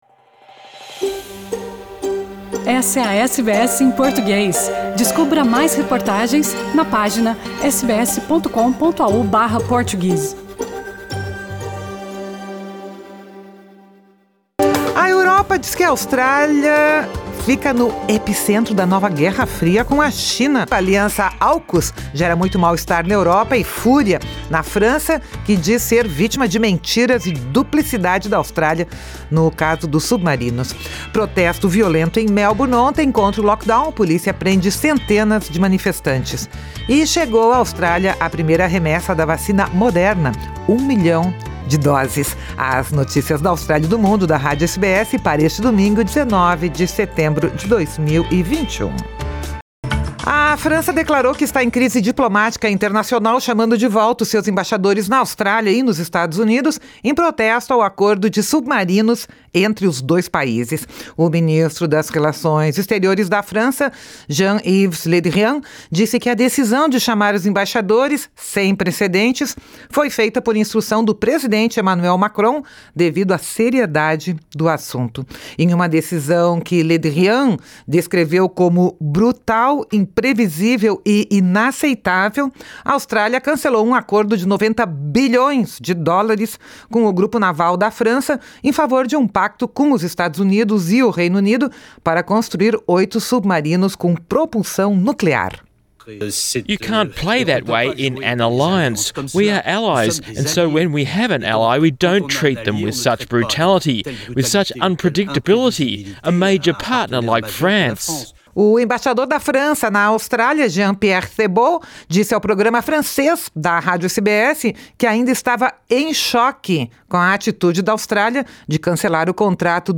Notícias da Austrália e do Mundo | 19 de setembro de 2021 | SBS Portuguese